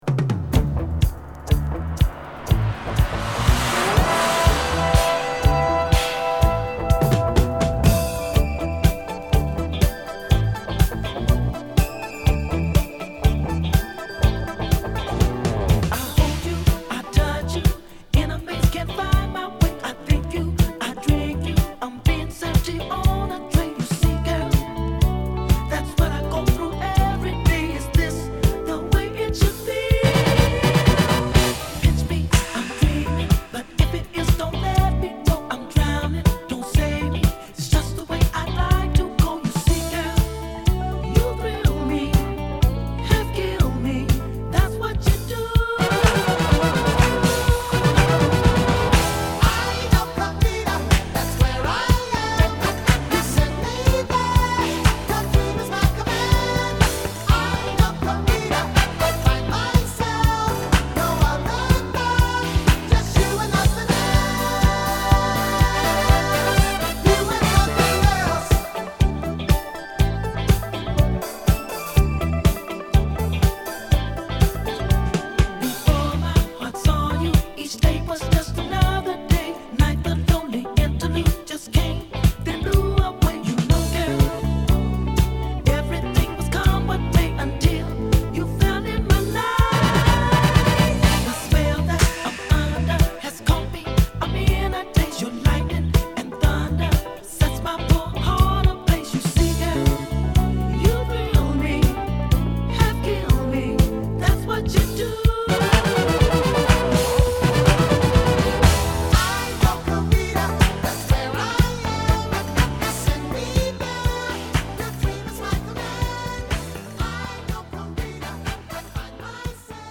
＊B1終わりからB3にかけて傷あり。プチノイズ出ます